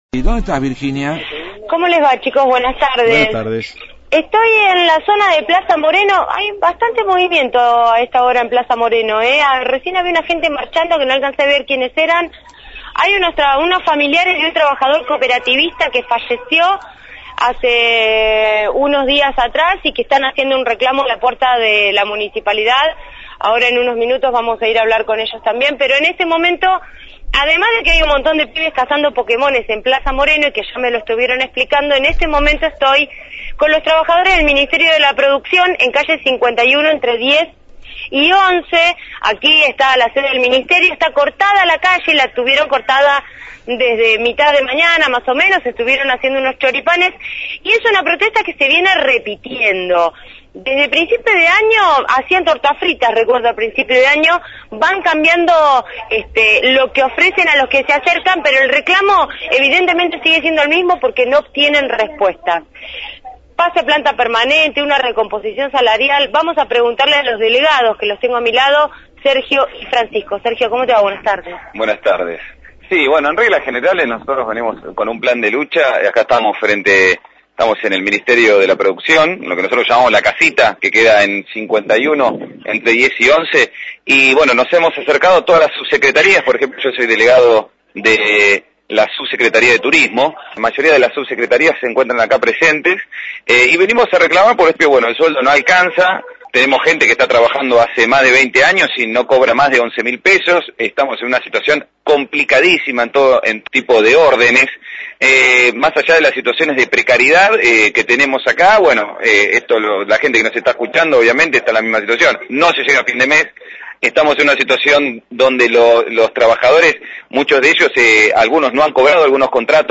desde calle 51 e/ 10 y 11, donde trabajadores del Ministerio de la Producción reclaman la precarización en el sector.